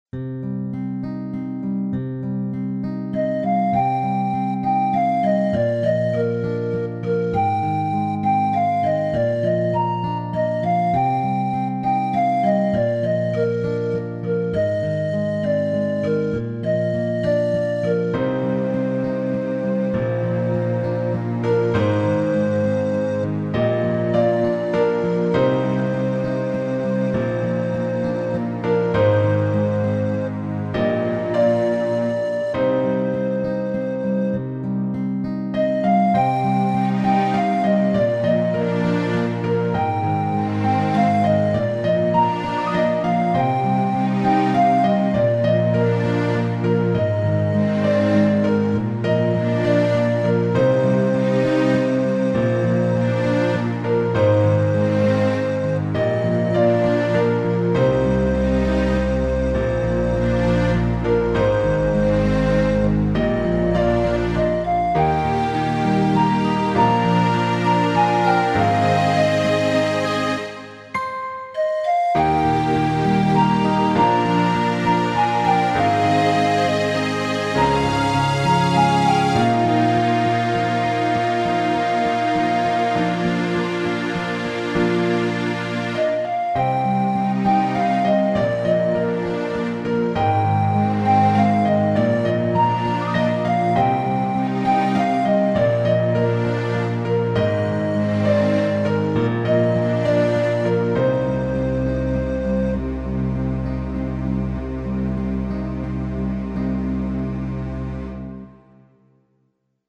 嵐に吹き飛ばされた花の残骸…。悲しい雰囲気の曲。